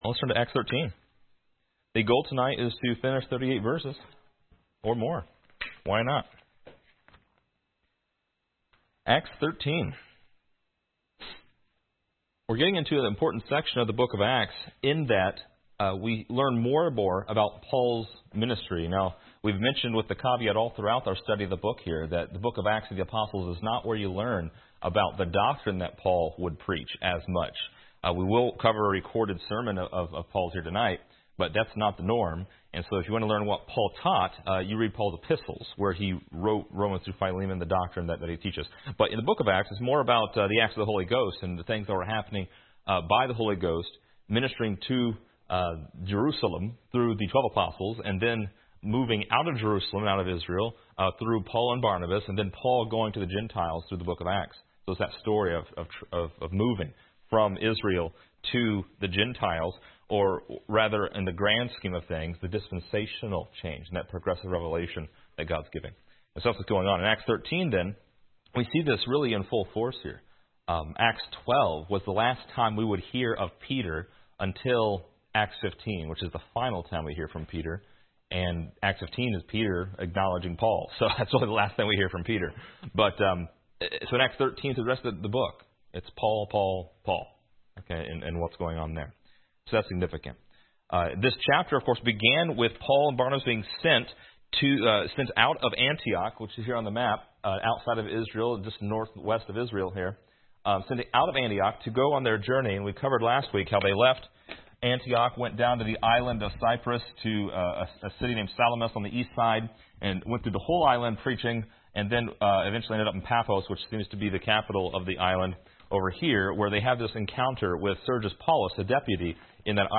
Download MP3 | Download Outline Description: This lesson is part 33 in a verse by verse study through Acts titled: Paul Turns to the Gentiles .